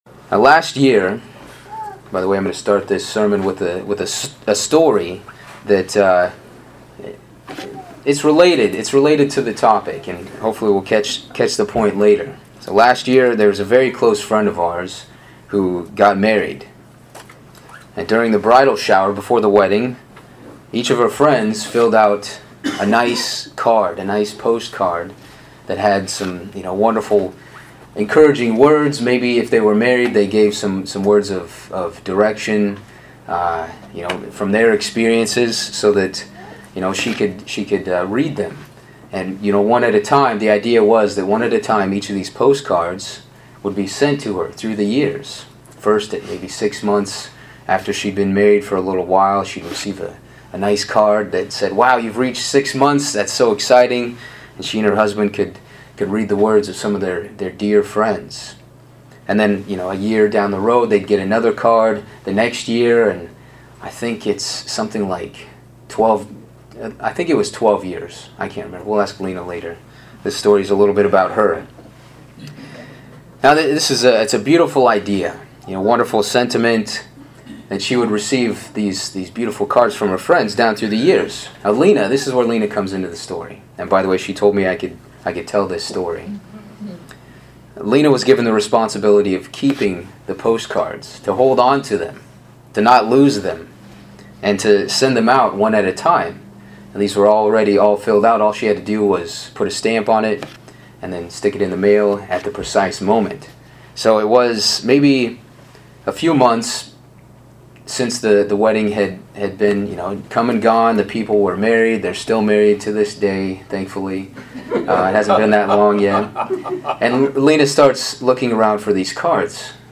In this message, the background of the Church at Ephesus is discussed as well as points of praise, points of correction and lessons for our congregations today. This sermon is the second in a series based on the letters to the congregations found in the book of Revelation.